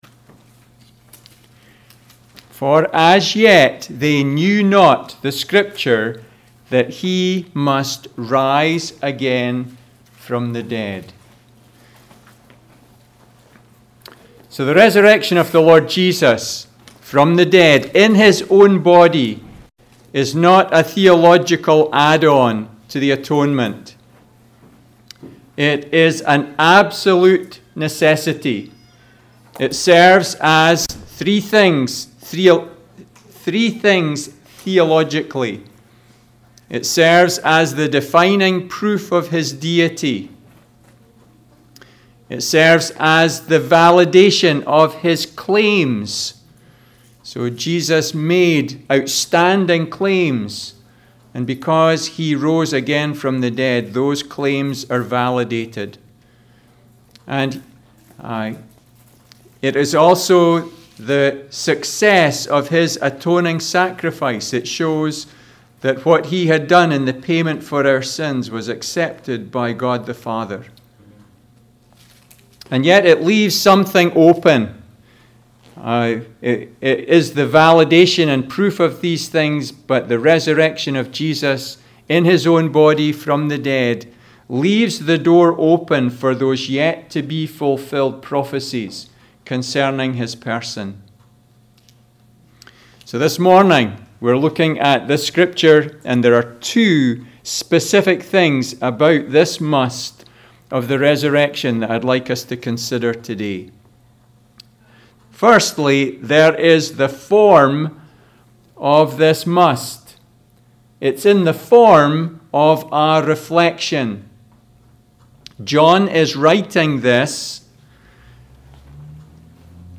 Passage: John 20 Service Type: Sunday School